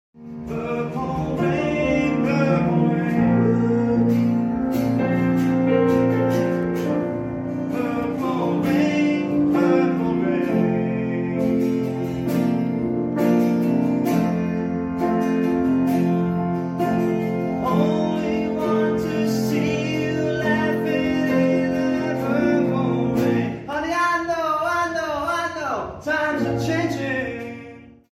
Pre-concert warmup